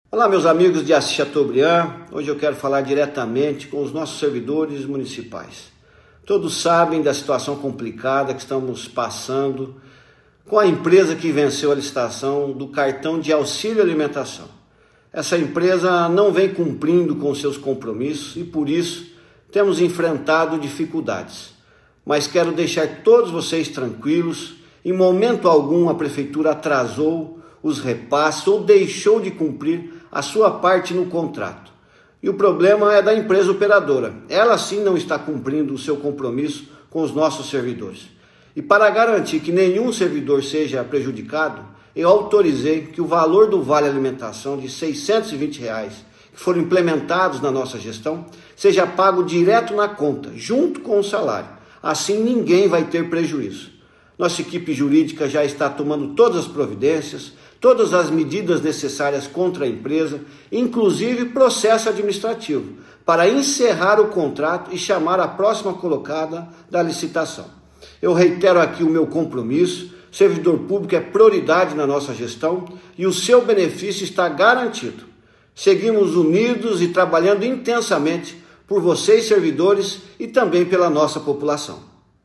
Prefeito de Assis Chateaubriand se pronuncia sobre situação do Cartão Face Card - Portal Boa Notícia
Audio-Prefeito-Marcel-ValeRefeicao.mp3